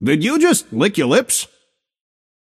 Shopkeeper voice line - Did you just… lick your lips?